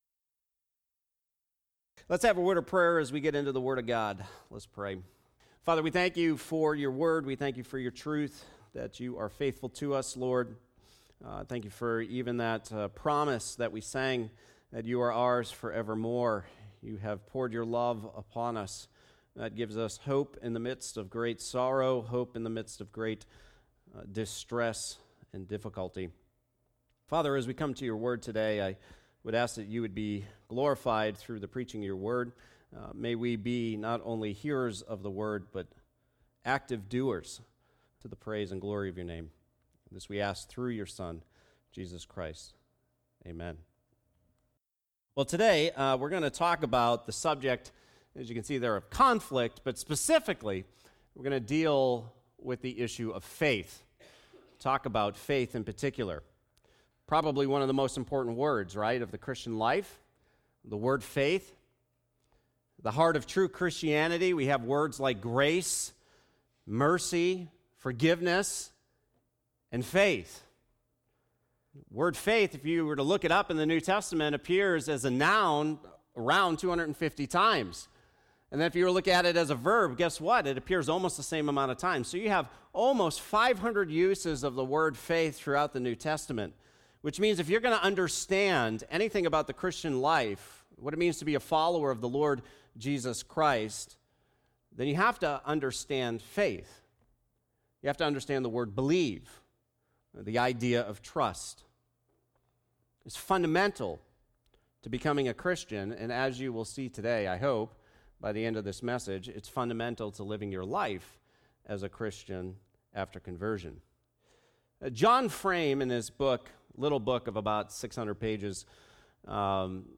Conflict: A Redemptive Opportunity - The Importance of Faith Hebrews 11:1 Sermon 4 of 10